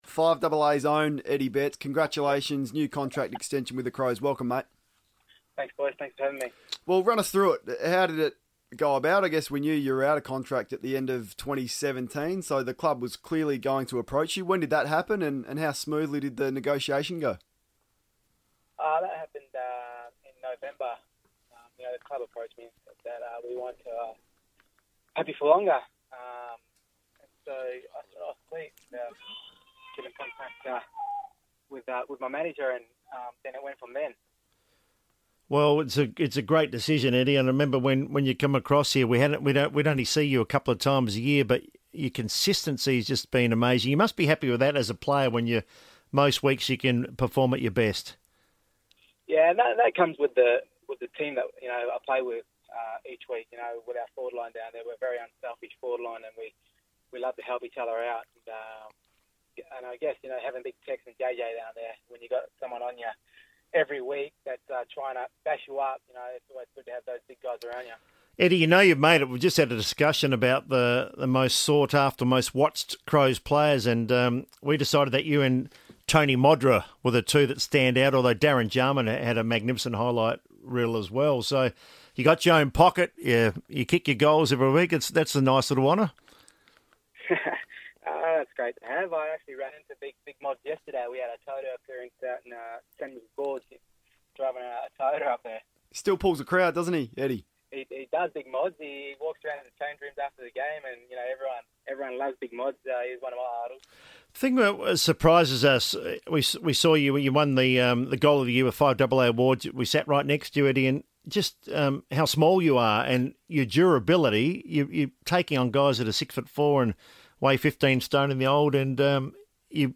Newly re-signed Crow Eddie Betts joined the FIVEaa Sports Show after he re-committed to the Club until at least the end of 2020.